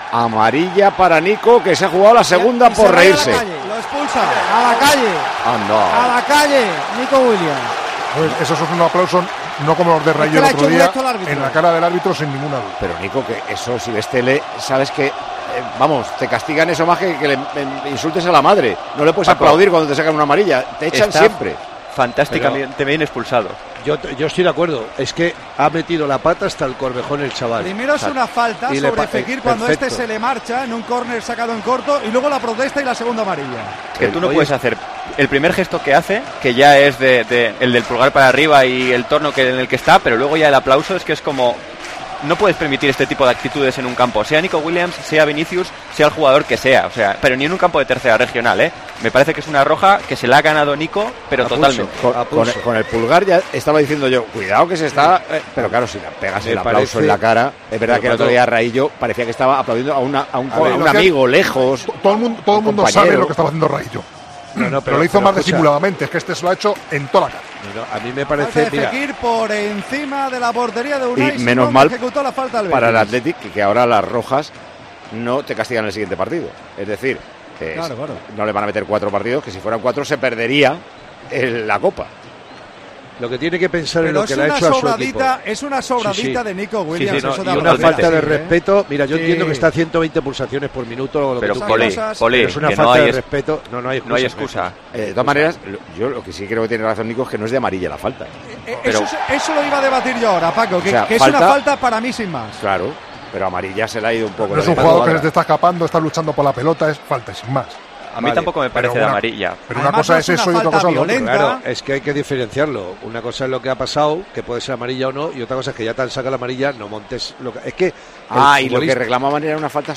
Así vivimos la expulsión de Nico Williams en el Villamarín